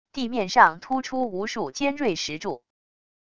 地面上突出无数尖锐石柱wav音频